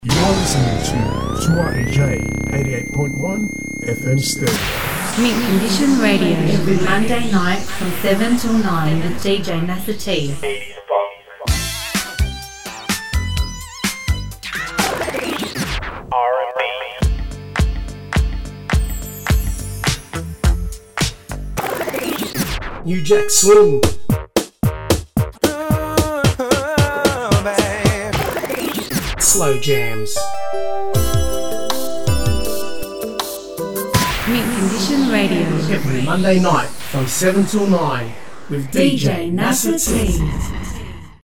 ROCKSTEADY FLASHBACK 2 hour 80’s Funk special